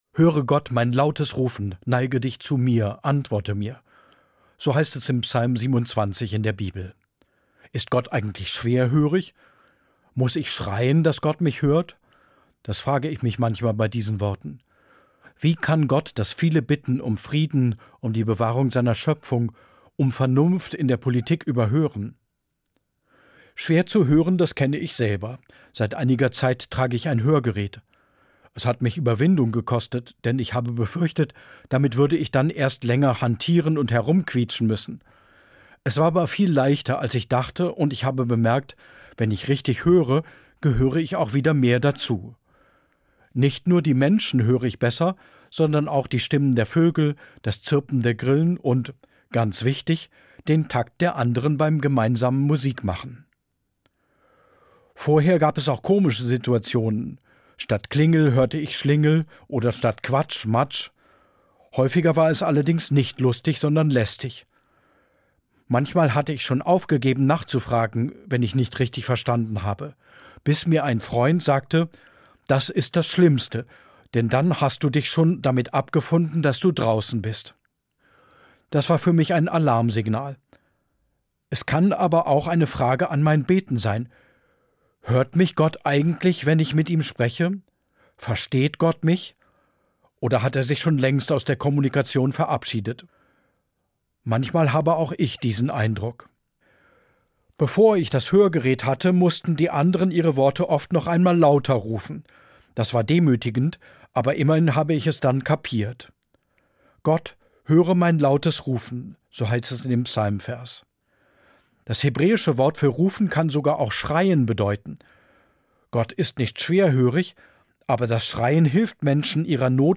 Katholischer Theologe, Kassel